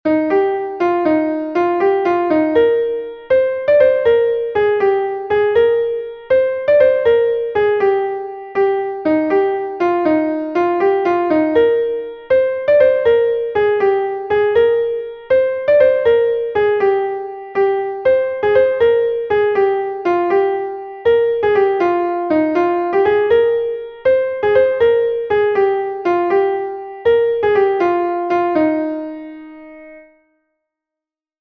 Ton Bale Leskoed est un Bale de Bretagne